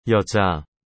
Em coreano, ‘mulher’ se diz 여자 (yeoja).